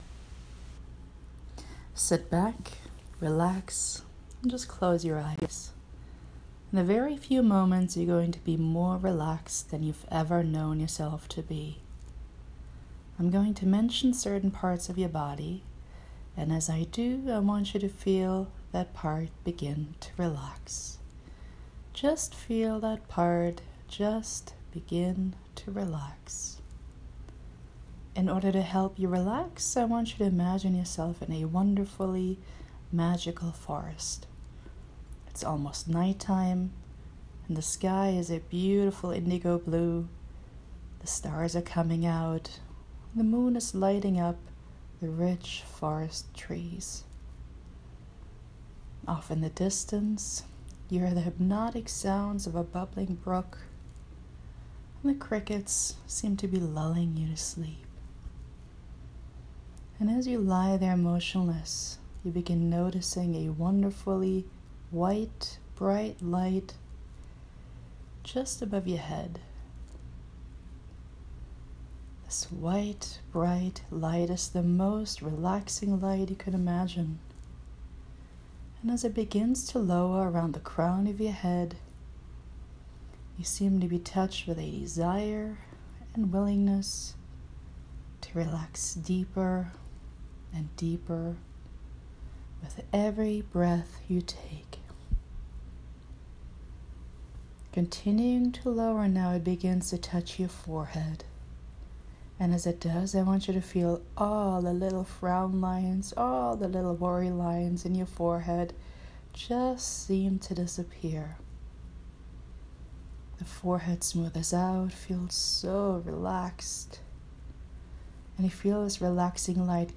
LISTEN TO THIS BODY SCAN
If you're having trouble falling asleep, listen to this 10-minute body scan. You will be asleep before it's done and you will feel connected to your body in a powerful way, while allowing your brain to calm down.